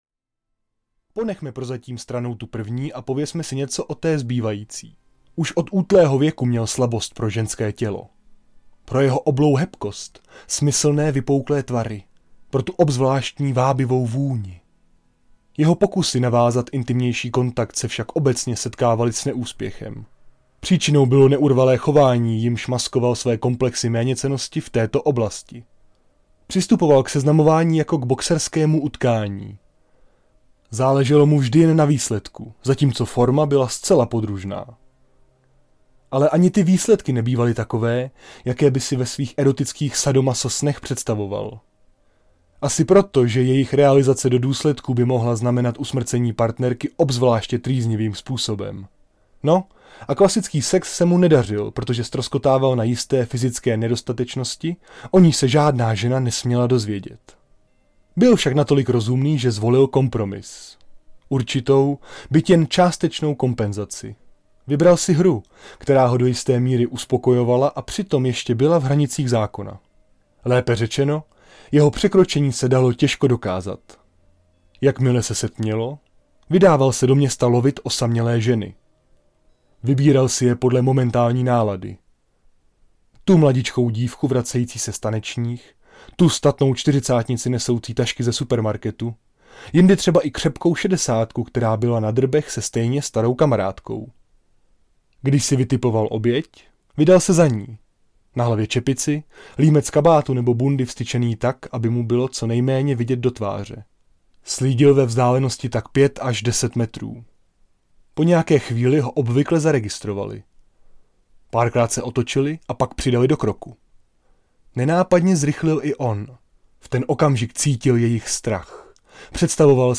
Mízožravci audiokniha
Ukázka z knihy